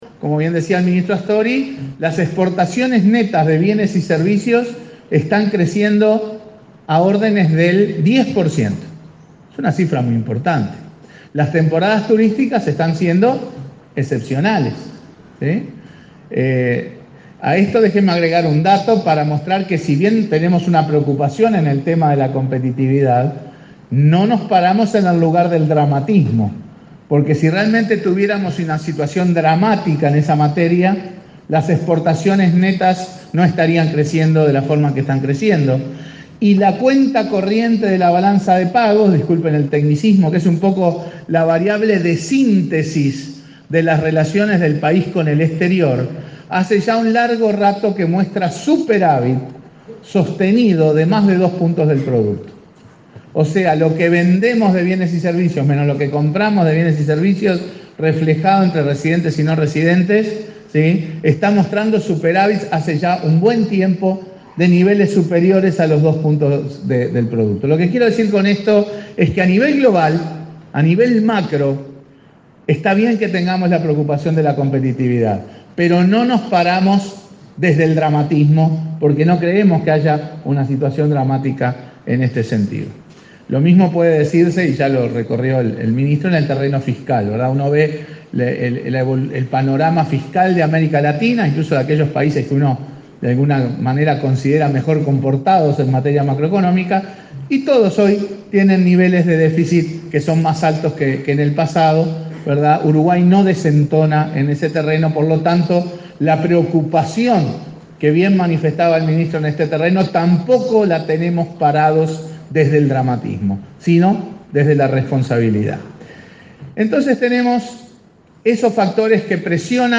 “La flexibilidad cambiaria es fundamental y ha servido como colchón para los shocks externos, que han sido enormes en los últimos 11 años”, sostuvo el presidente del BCU, Mario Bergara, en ACDE. Dijo que en el tipo de cambio incide la incertidumbre y volatilidad y acotó que en el último año y poco el banco absorbió más de US$ 4.000 millones; “lo hacemos por responsabilidad y porque pensamos en el sector real de la economía”.